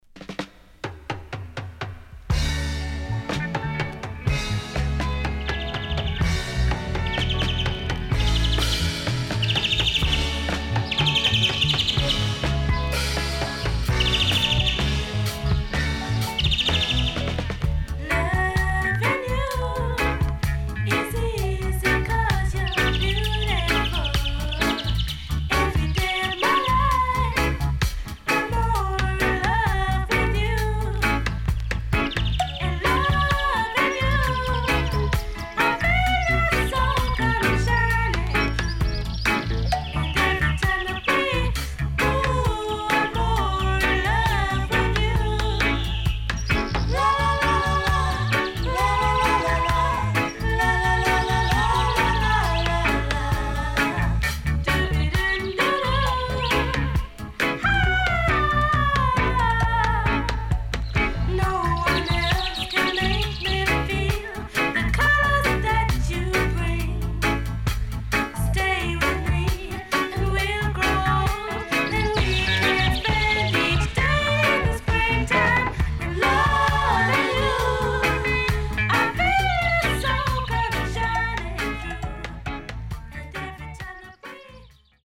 【12inch】
SIDE A:所々チリノイズがあり、少しプチノイズ入ります。